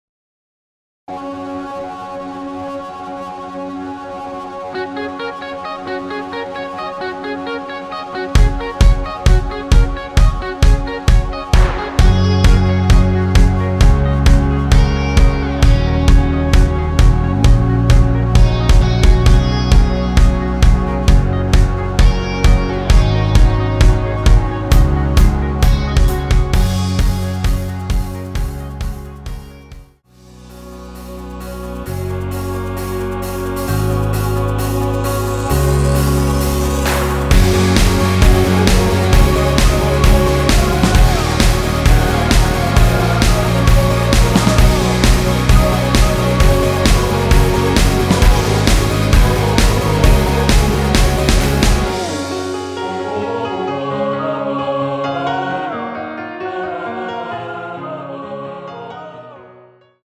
원키에서(-3)내린 MR입니다.
Gb
앞부분30초, 뒷부분30초씩 편집해서 올려 드리고 있습니다.
중간에 음이 끈어지고 다시 나오는 이유는